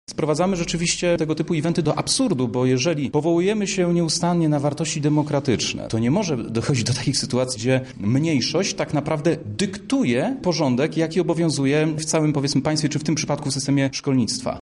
Prawo Oświatowe podkreśla że nauka w szkołach opiera się na zasadach etycznych szanując wartości chrześcijańskie – mówi radny-elekt Marcin Jakóbczyk.